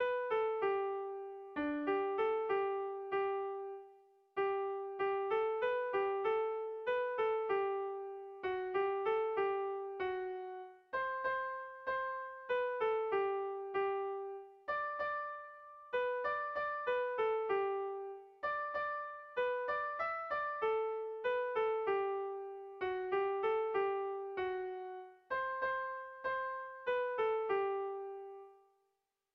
Dantzakoa
Zortziko txikia (hg) / Lau puntuko txikia (ip)